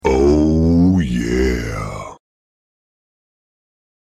oh-yeah-sound-effect_XXSgWrG.mp3